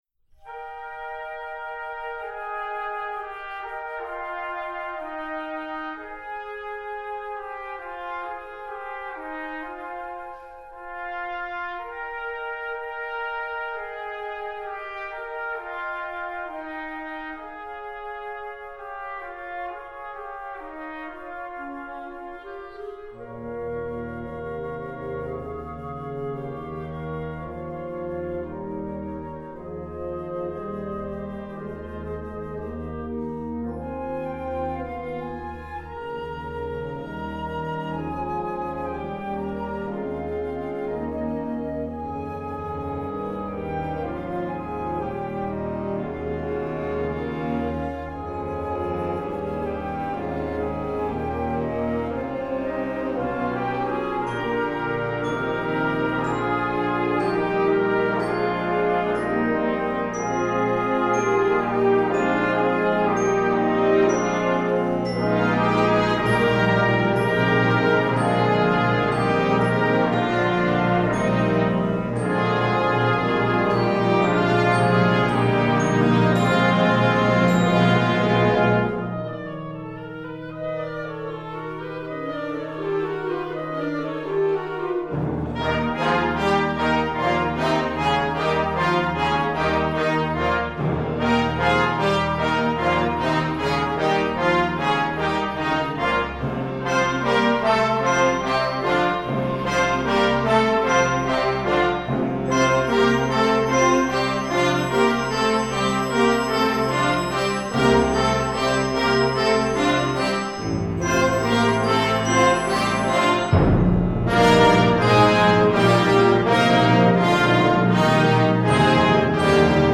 classical, children